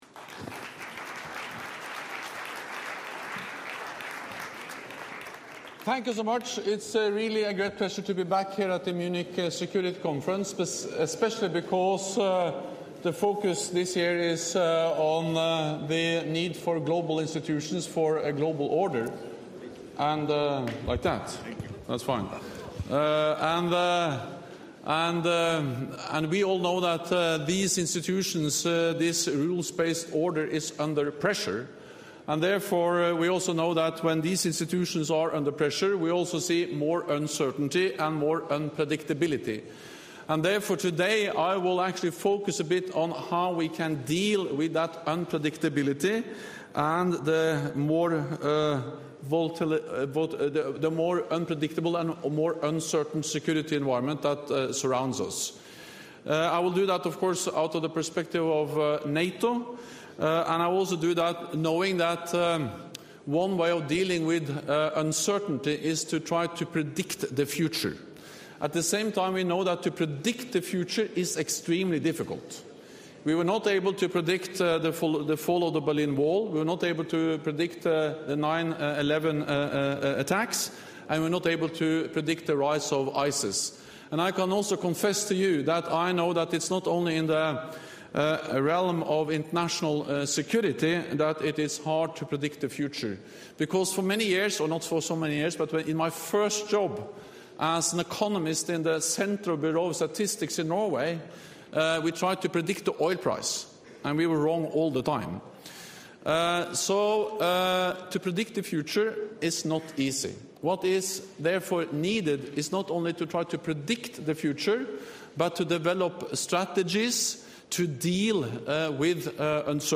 Speech
by NATO Secretary General Jens Stoltenberg at the Munich Security Conference